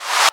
VEC3 FX Reverse 48.wav